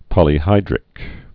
(pŏlē-hīdrĭk)